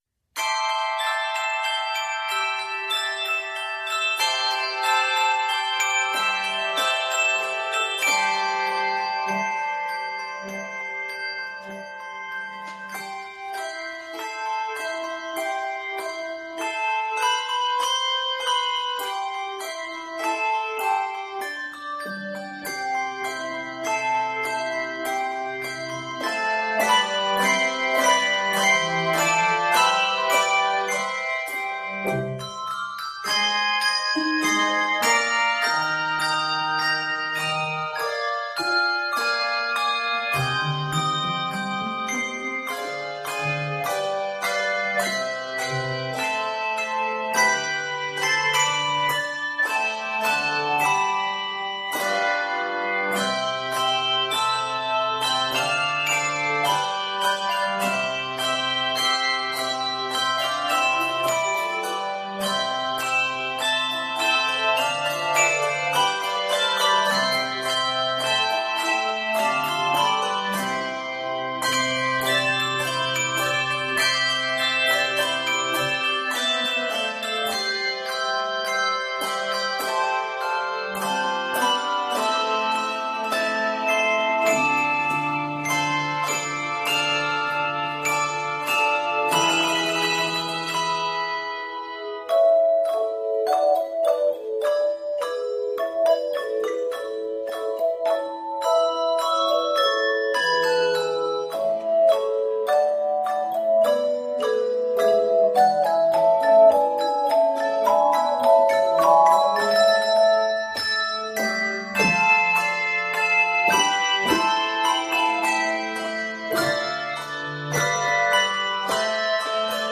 Set in C Major and A Major, it is 71 measures in length.